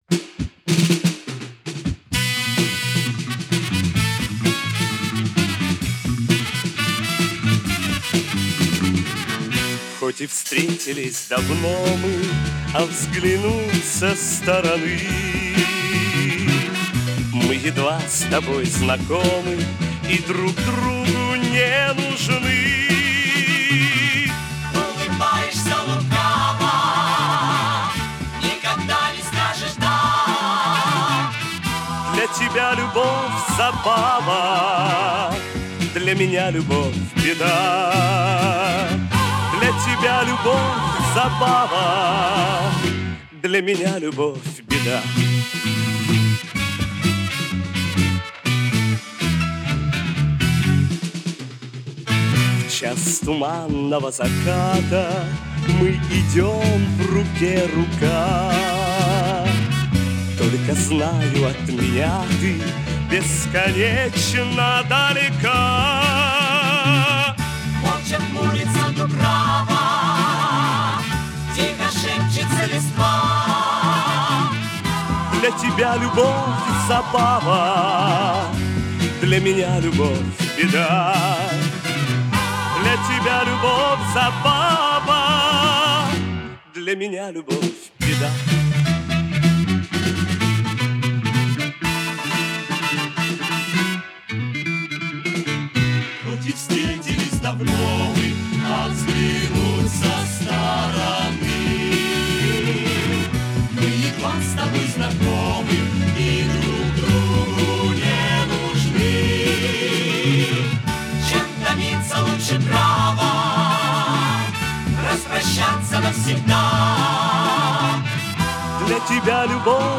Жанр: Pop
Формат: CD, Stereo, 2LP In 1CD, Album, Compilation, Reissue
Стиль: Rock, Pop